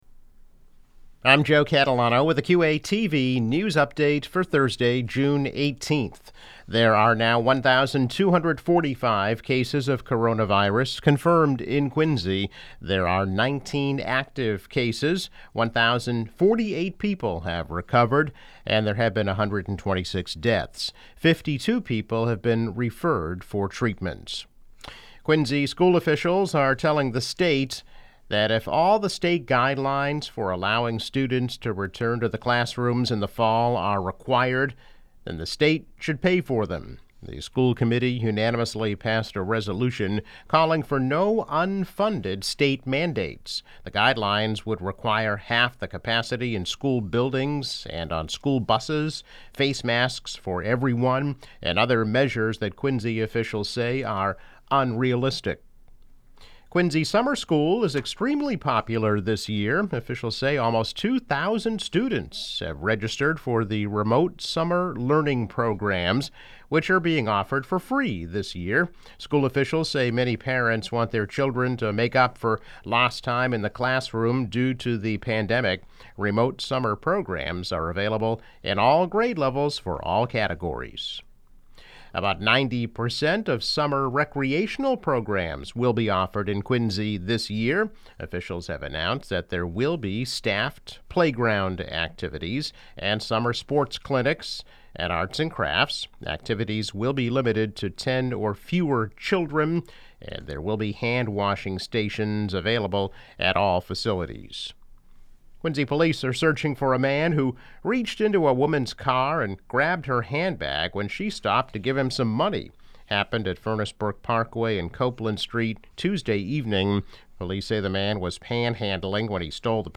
Daily news update.